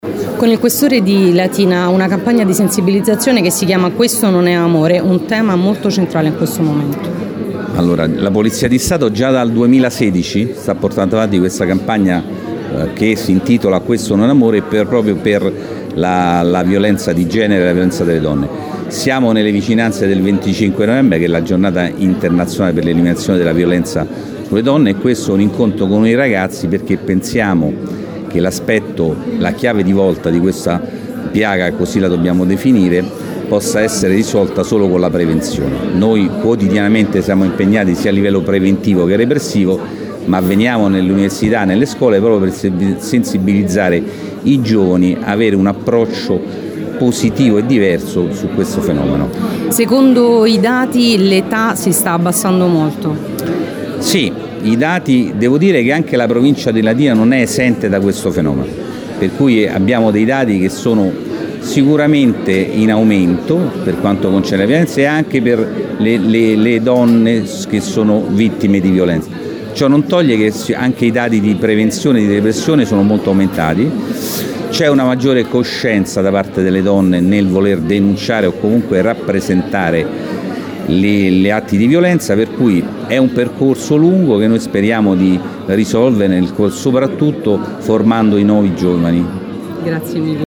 L’evento, che precede la Giornata Internazionale per l’eliminazione della violenza contro le donne, si è tenuto nella sala conferenze del Polo Pontino della Sapienza con la partecipazione di molti attori istituzionali.
Le interviste contenute in questo articolo
IL QUESTORE DI LATINA FAUSTO VINCI